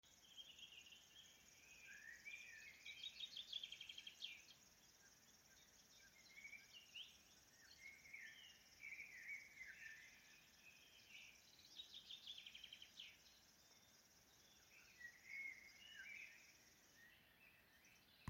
Putni -> Ķauķi ->
Upes ķauķis, Locustella fluviatilis
Administratīvā teritorijaSaldus novads
StatussDzied ligzdošanai piemērotā biotopā (D)